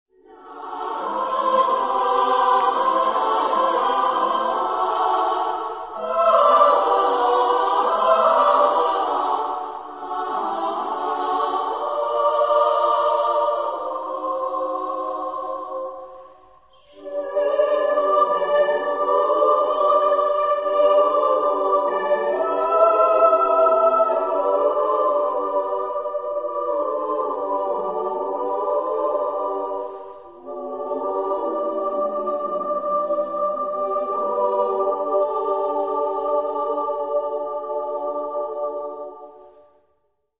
Genre-Style-Forme : contemporain ; Poème ; Profane
Caractère de la pièce : poétique
Tonalité : la mineur